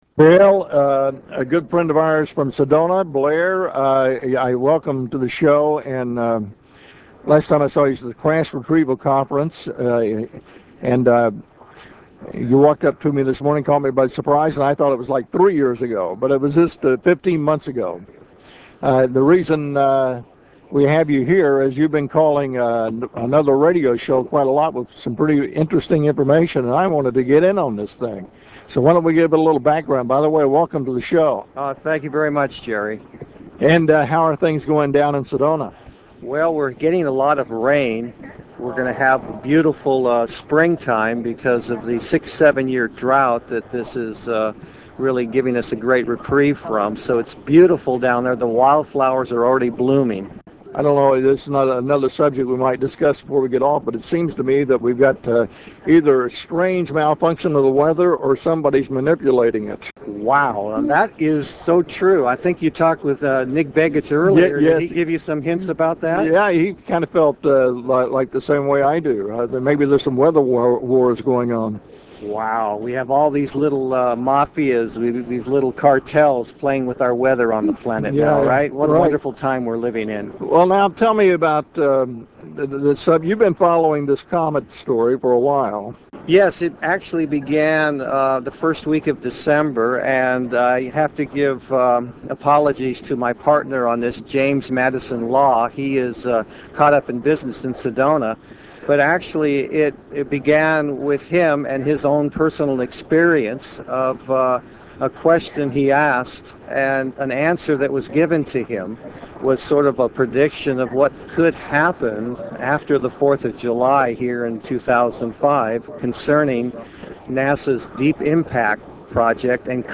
14th Annual International UFO Congress Convention and Film Festival Laughlin, Nevada March 6 -12, 2005